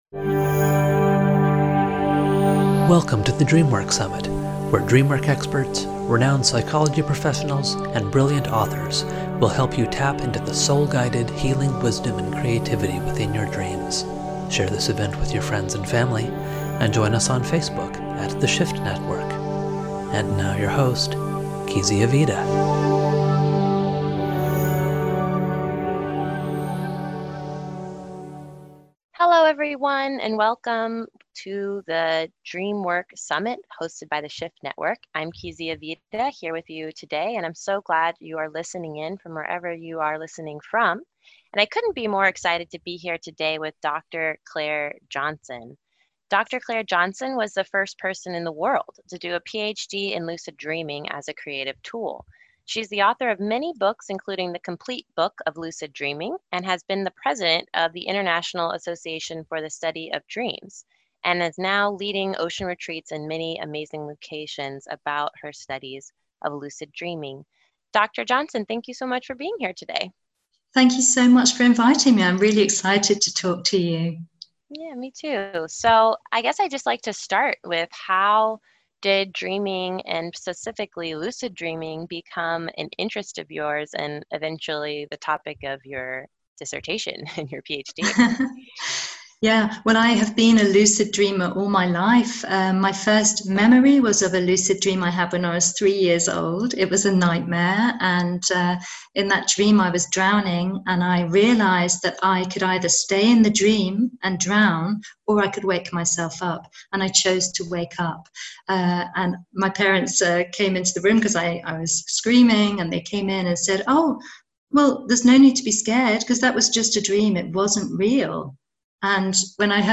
Below, you can listen to my talk on how to release fear in nightmares and scary sleep paralysis episodes to create space and energy in your life for joy and lucid living.